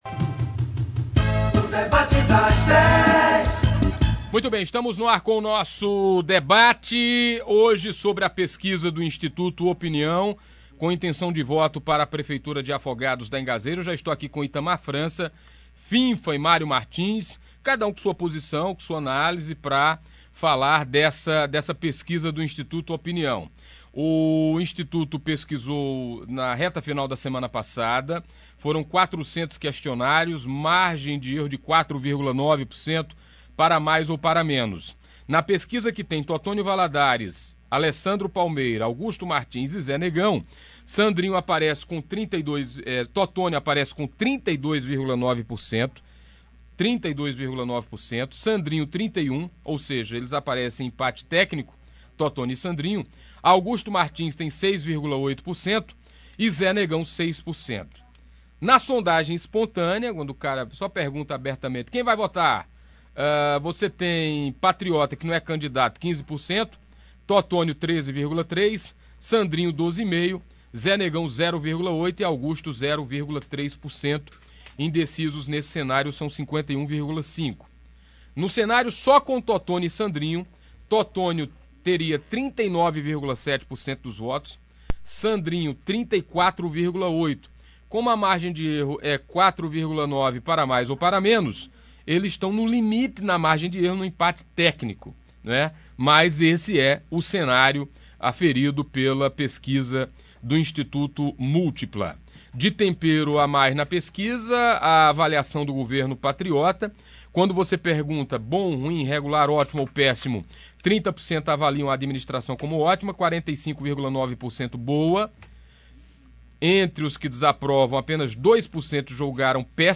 O Debate das Dez do programa Manhã Total, da Rádio Pajeú, escutou hoje os nomes citados pela pesquisa Opinião e Blog do Magno com suas impressões sobre os números.